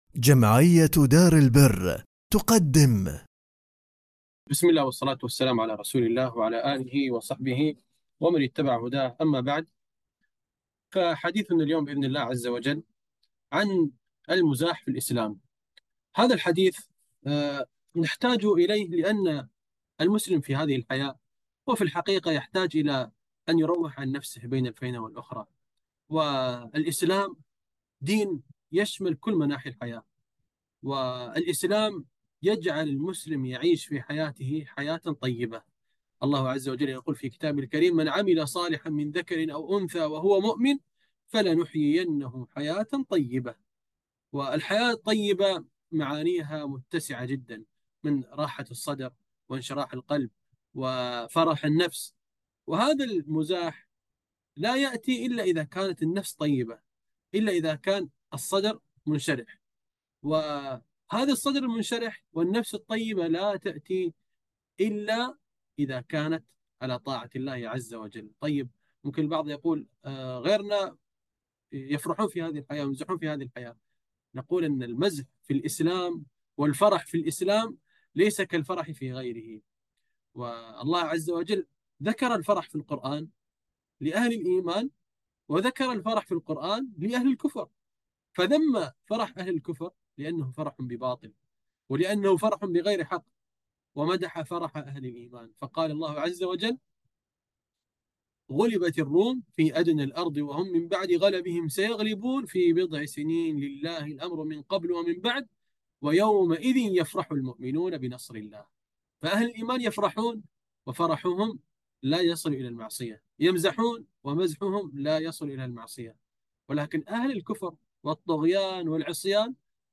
محاضرة مزاح بلا ذنوب (ضوابط المزاح في الإسلام)